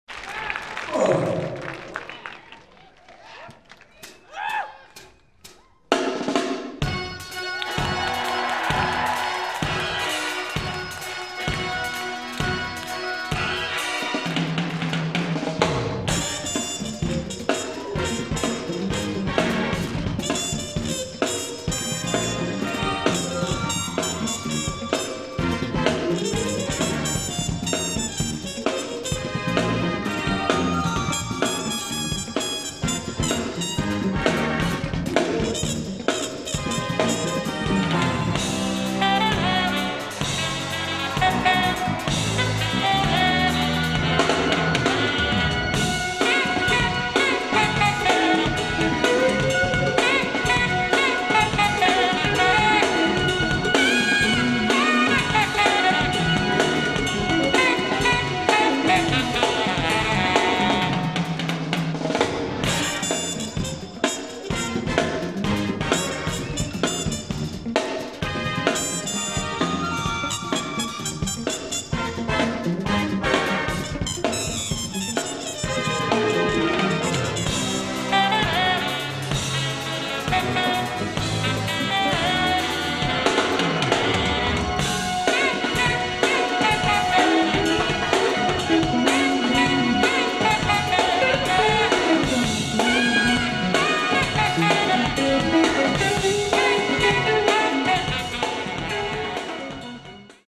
放送音源を過去最高クオリティーで収録！！
※試聴用に実際より音質を落としています。